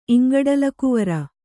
♪ iŋgaḍalakuvara